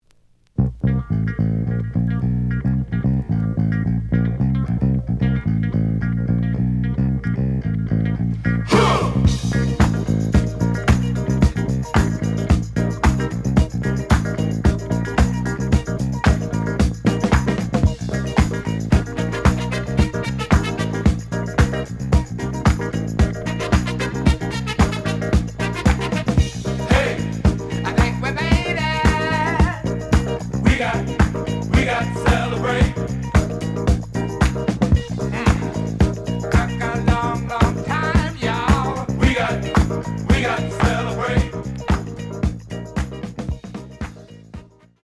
The audio sample is recorded from the actual item.
●Genre: Funk, 70's Funk
Edge warp. But doesn't affect playing. Plays good.)